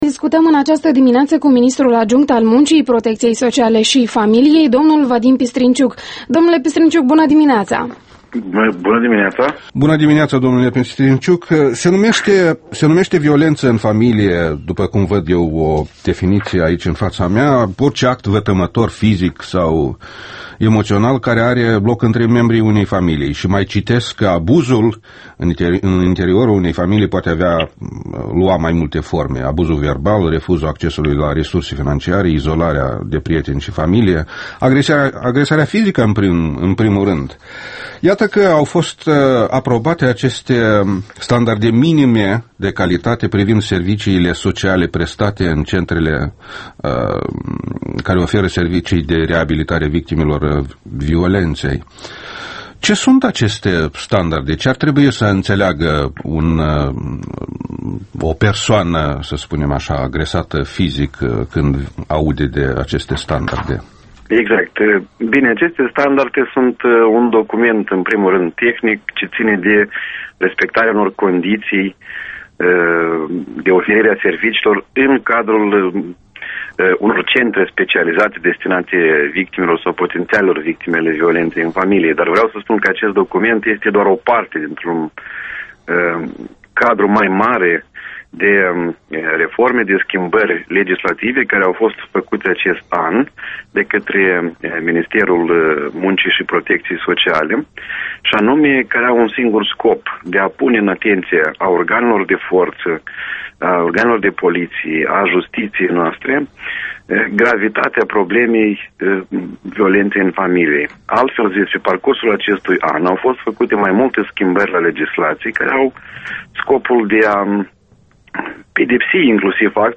Interviul matinal EL: cu Vadim Pistrinciuc